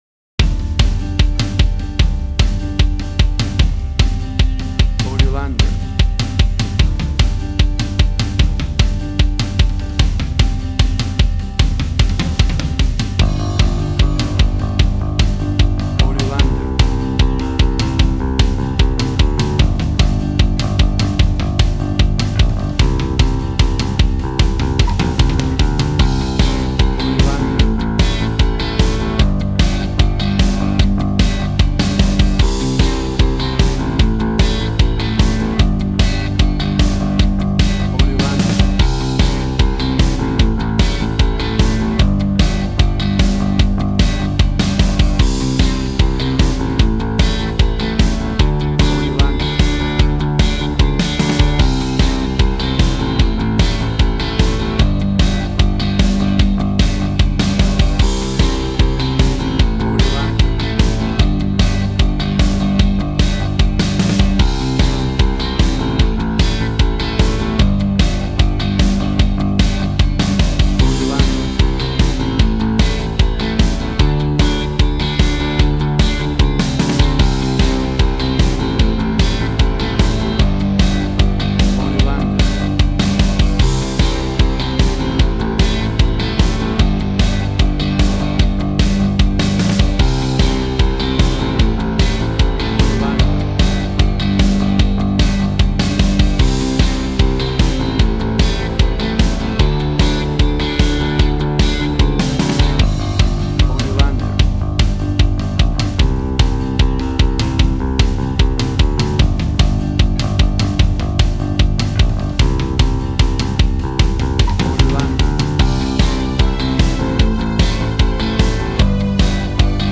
Tempo (BPM): 150